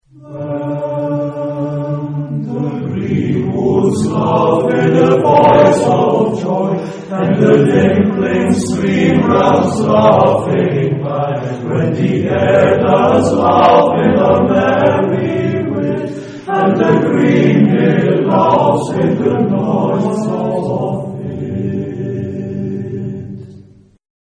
Genre-Style-Form: Partsong ; Poem ; Folk music
Mood of the piece: joyous
Type of Choir: SSAA  (4 children OR women voices )
Tonality: G major